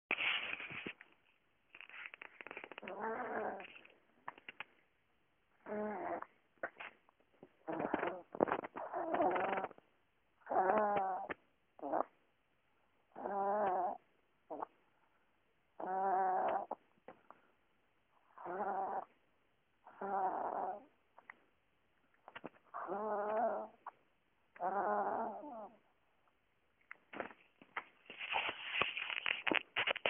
I really, really like it when my daddy rubs my back.
sisbackrub2009jan.mp3